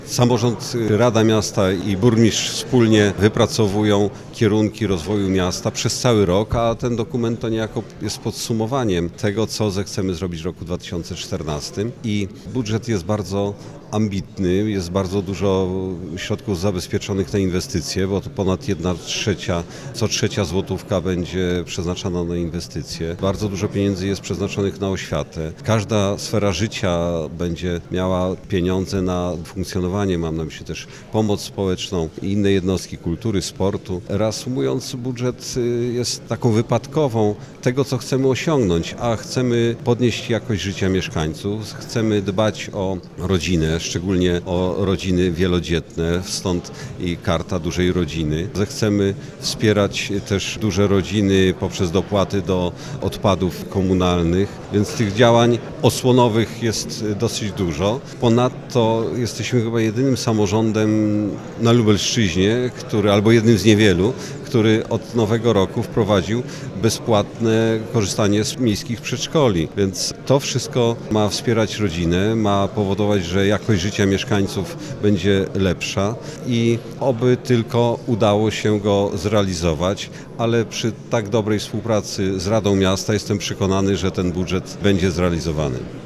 To budżet ambitny, ale też świadczący o stabilnej pozycji finansowej miasta - mówi burmistrz Andrzej Jakubiec.